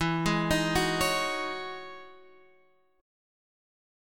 EM13 chord